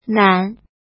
怎么读
nǎn
nan3.mp3